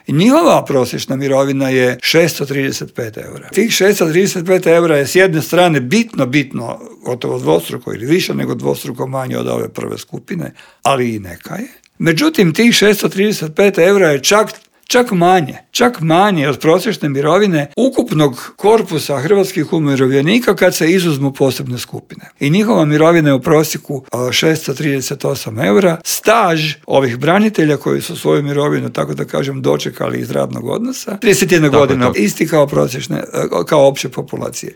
Saborski zastupnik iz redova platforme Možemo! Damir Bakić u Intervjuu Media servisa poručio je da će se povećanje cijena goriva preliti i na druga poskupljenja: "Teret ove krize podnijet će građani i umirovljenici".